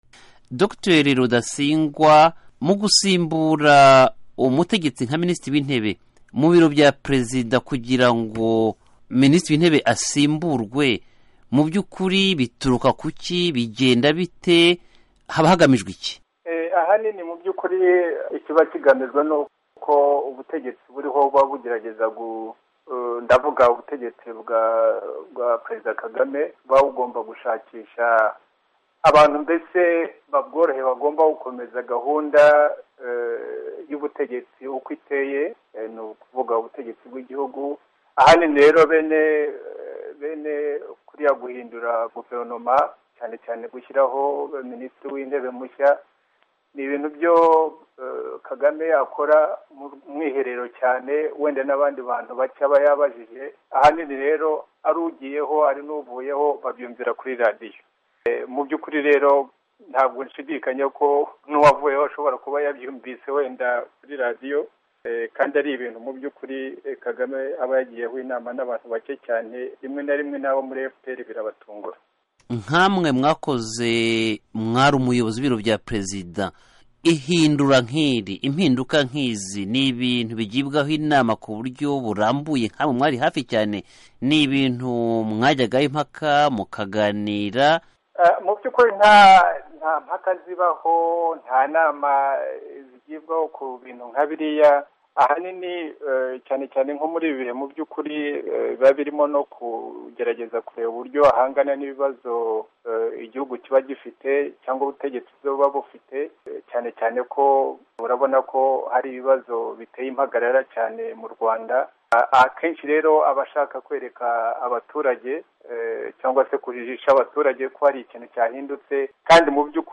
Ese ministri w'intebe ashyirwaho ate? Iki kibazoi twakibajije Dr. Rudasingwa Theogene, wigeze kuba umuyobozi w'ibiro bya Perezida w'u Rwanda Paul Kagame. Aragnaira n'umunyamakuru w'Ijwi ry'Amerika